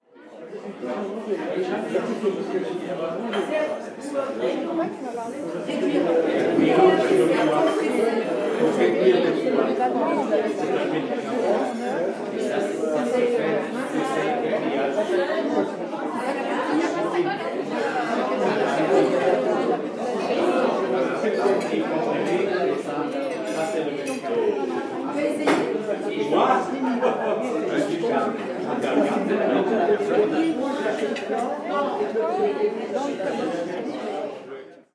描述：在这段录音中，有许多声音我都留了下来，以使人们对声景有一个透视。人们期望这个小的保护性公园是安静的，是一个容易获得出色的实地录音的地点。然而，在录音开始后不久，一辆汽车从附近的道路上驶来，尽管道路在麦克风的后面，但你可以听到碎石被弹到附近停放的汽车上的声音在录音快结束时，一个男人牵着一只狗经过。虽然该男子打了一个愉快的招呼，但狗在经过停着的汽车时发出了咆哮。难道它闻到了留在家里的哈士奇的味道？索尼PCMD50在DC Millenium.
标签： 汽车 安静 录音 歌曲 音景
声道立体声